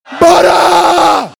Boraaa! - Botón de Efecto Sonoro